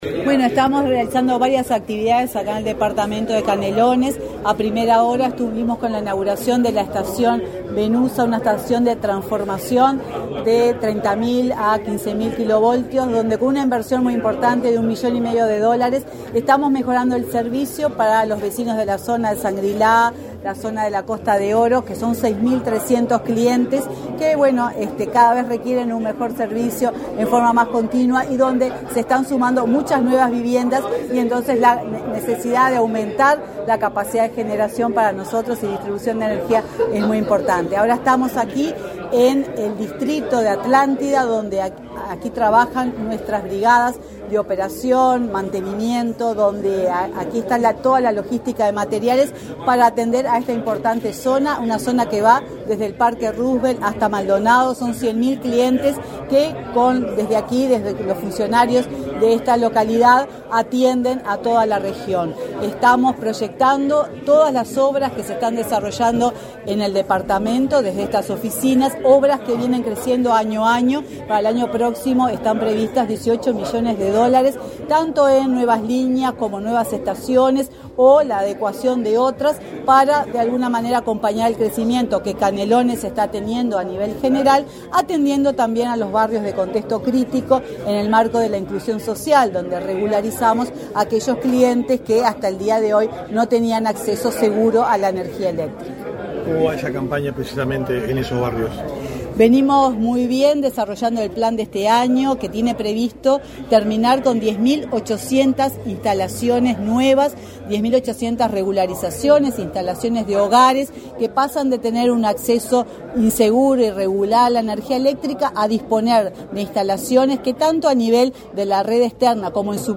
Declaraciones de la presidenta de UTE, Silvia Emaldi
La presidenta de UTE, Silvia Emaldi, dialogó con la prensa en Canelones, donde inauguró varias obras eléctricas.